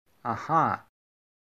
Звуки человека, аха
• Качество: высокое
Еще так может звучать мужское ага